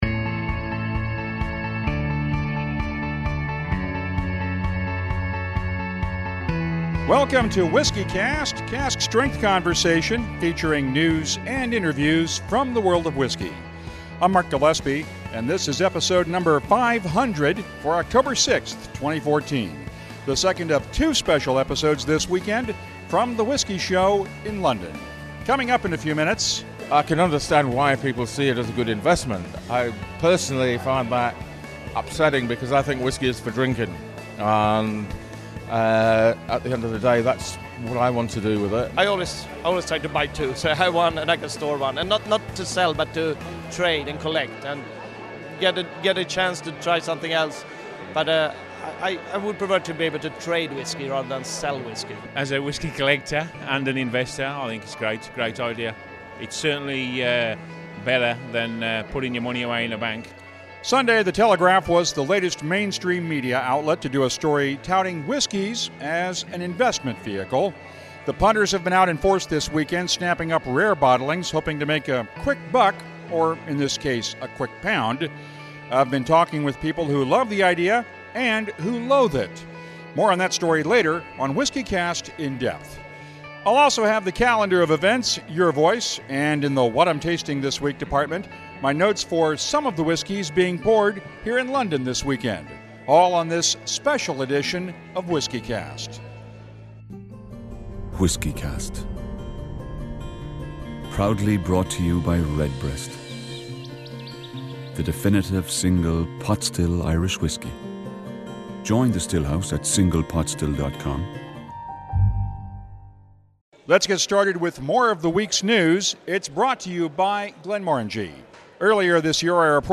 This is a special episode of WhiskyCast - not only is it the 500th episode, but it's the second of two episodes this weekend from The Whisky Show in London!